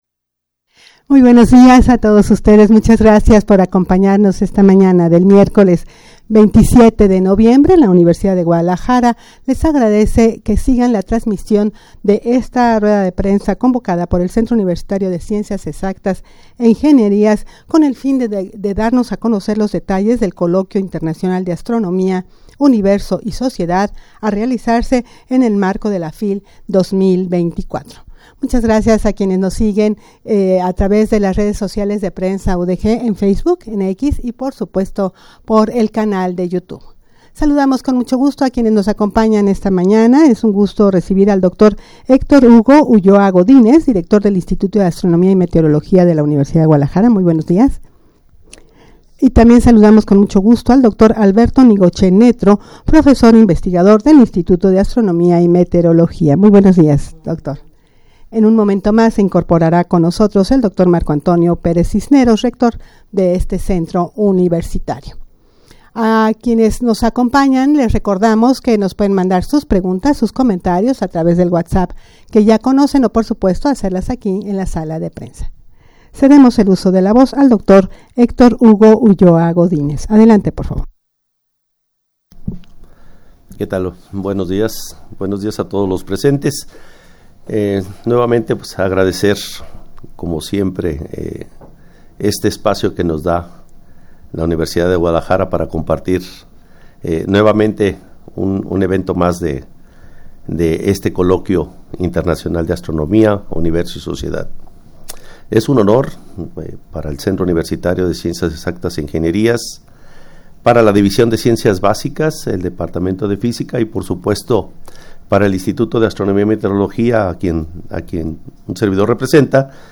rueda-de-prensa-para-dar-a-conocer-los-detalles-del-coloquio-internacional-de-astronomia-universo-y-sociedad.mp3